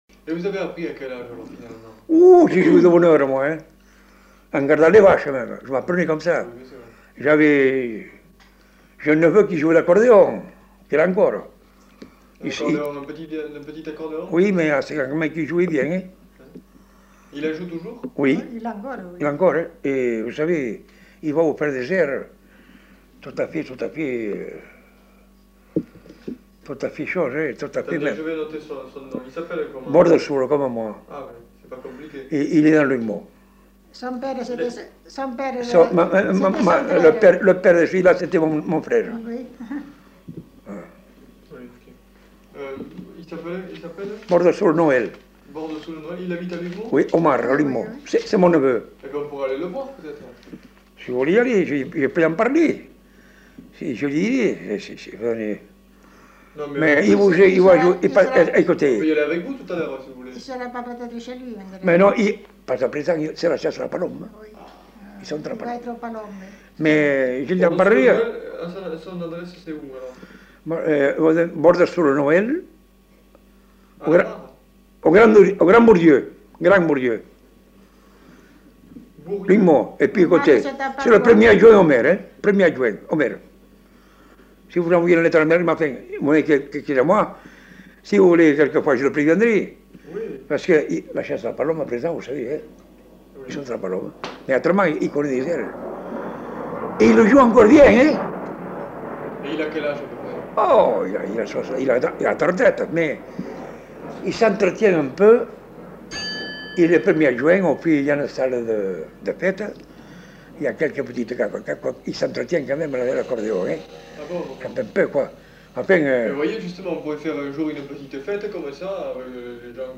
Aire culturelle : Bazadais
Genre : récit de vie